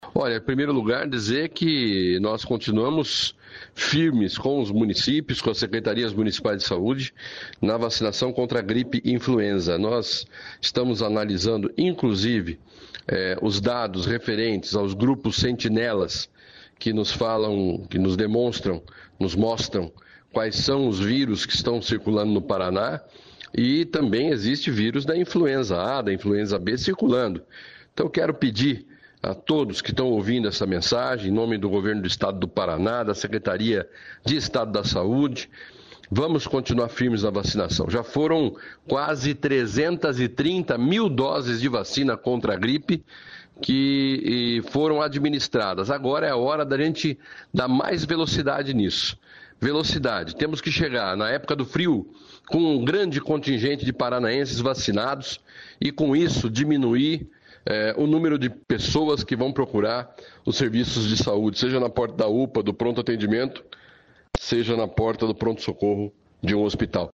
Sonora do secretário de Estado da Saúde, Beto Preto, sobre a vacinação contra a gripe